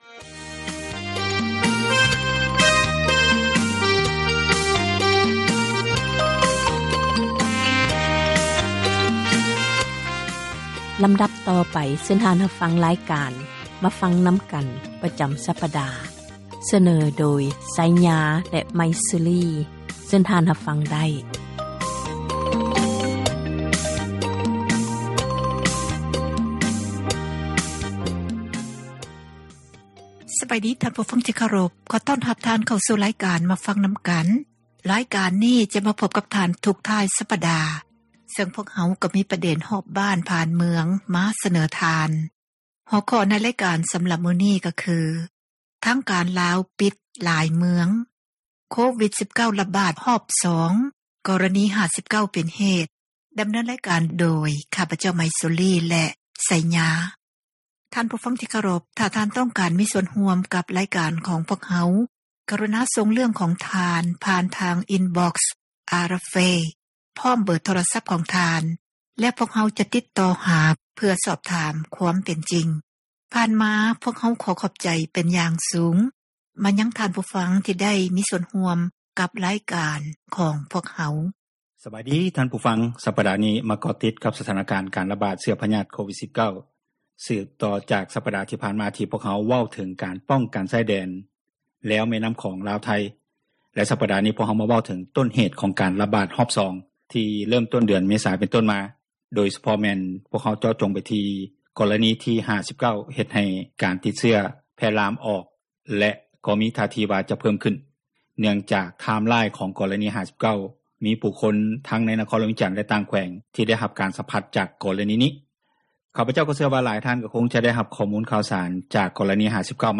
ການສົນທະນາ ໃນບັນຫາ ແລະ ຜົລກະທົບຕ່າງໆ ທີ່ເກີດຂຶ້ນ ຢູ່ປະເທດລາວ